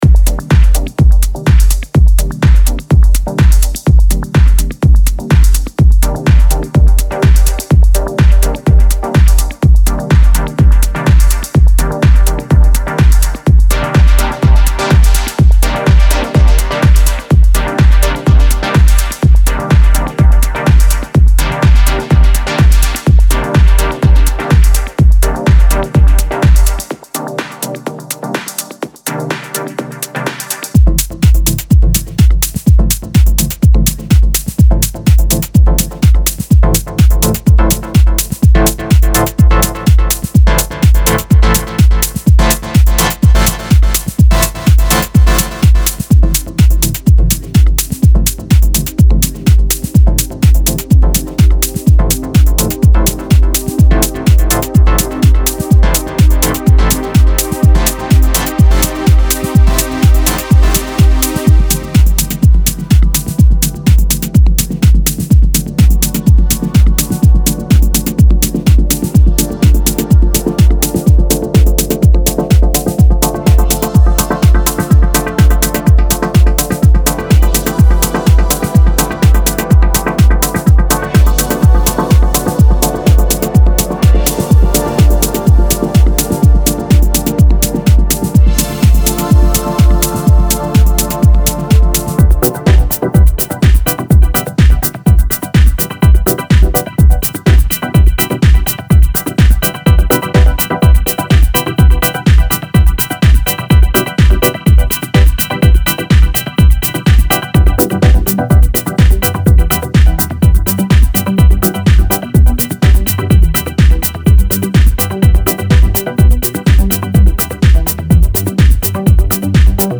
Hypnotic sequences, tech-infused chord, and progressive pads, Dive deep into the ethereal realm with the Evolving Synths… Get to grips with silky melodics and add that special touch to your tracks.
Please Note: Demo track contains drum sounds which are not included in the pack. This product contains synth loops only.